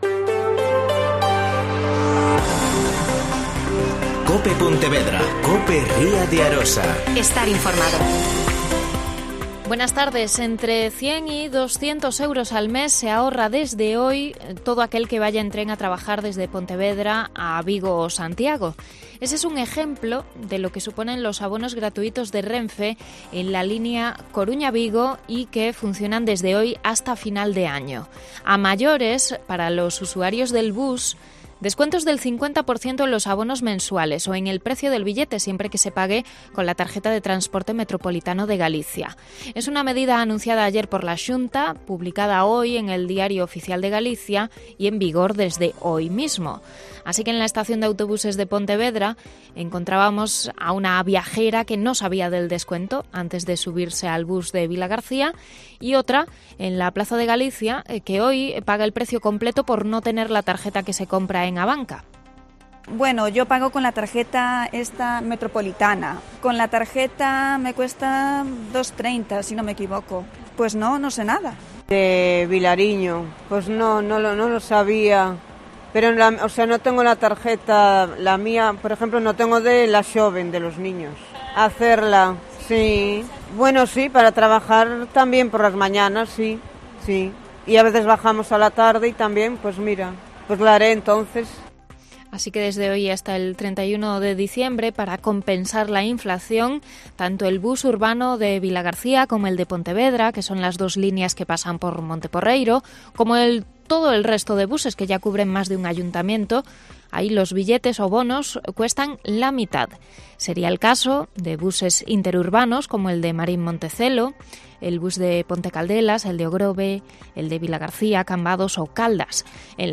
Mediodía COPE+ Pontevedra (Informativo 14:20h)